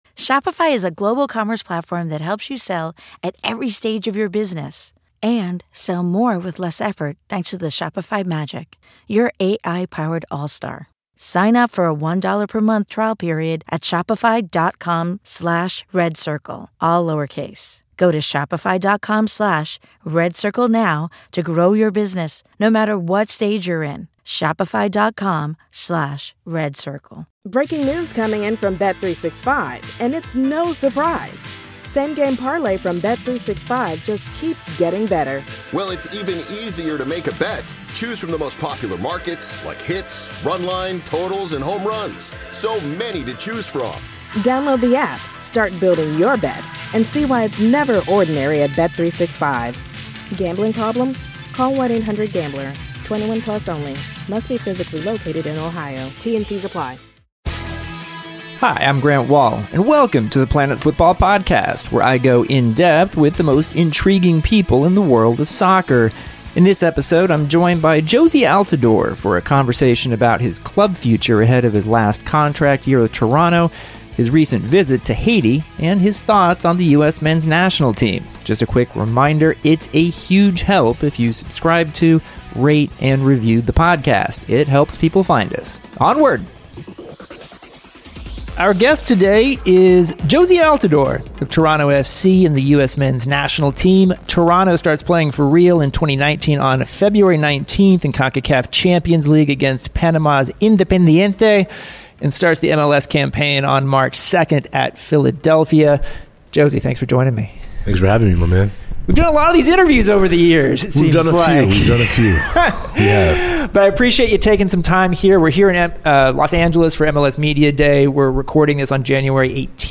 A conversation with Jozy Altidore